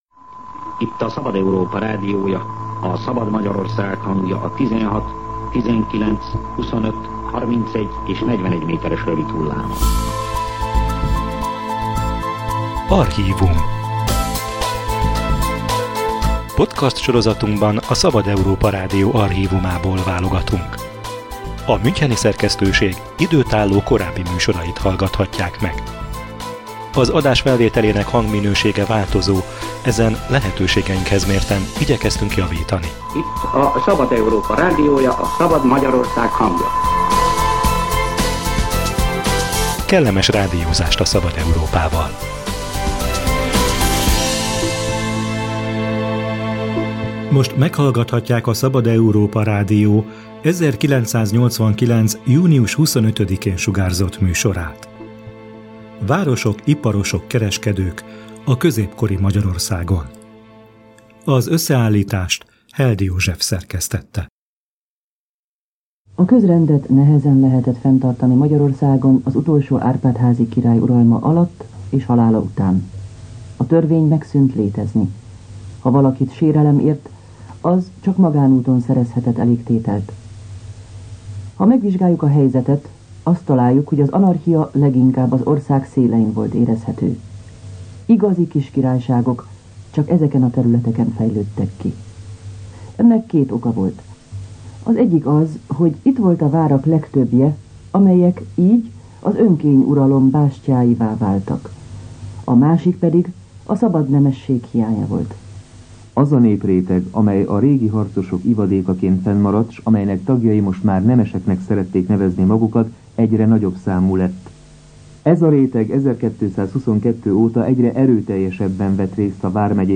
Ezzel az időszakkal foglalkozott a Szabad Európa Rádió 1989-es történelmi műsora.